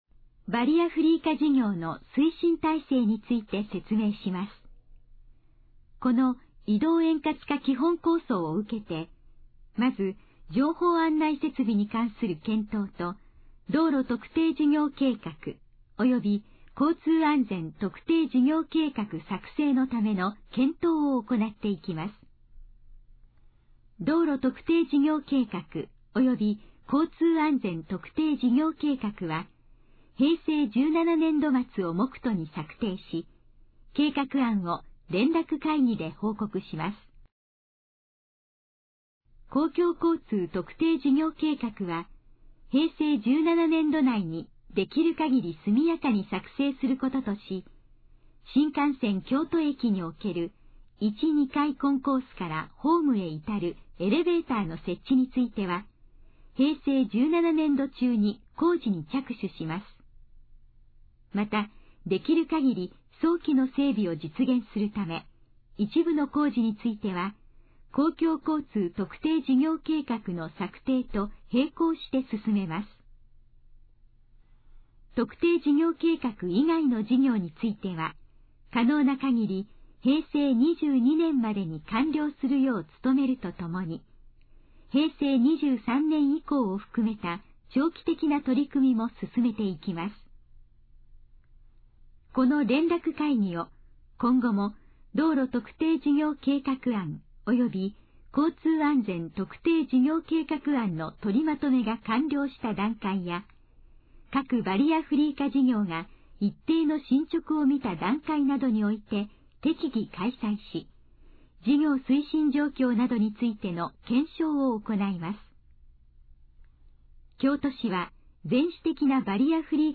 このページの要約を音声で読み上げます。
ナレーション再生 約257KB